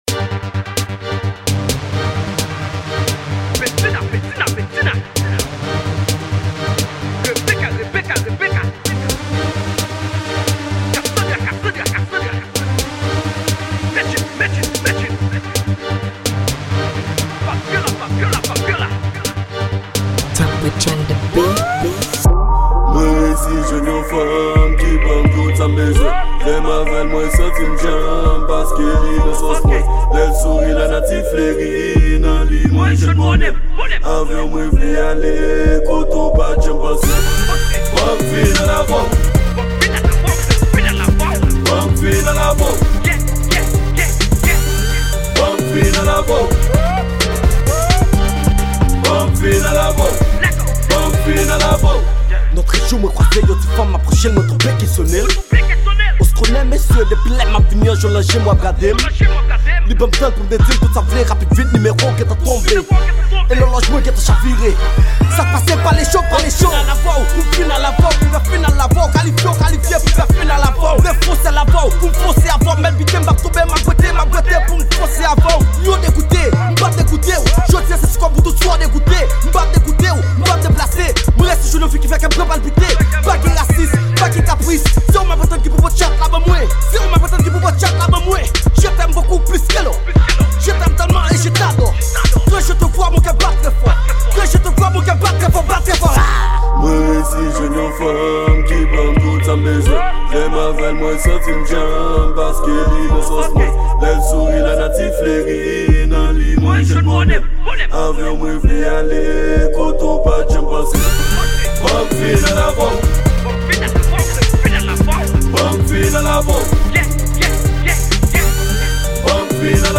Genre: Trap Hip-hop.